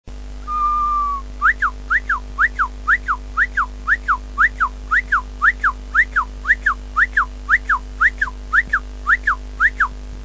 人間のまがい物 　・・・飼い主のあやしい口笛
前王朝の始祖鳥ブクのさえずりを小学生だった私が必死に真似、ヘイスケに古今伝授したもの。